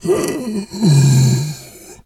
bear_pain_whimper_13.wav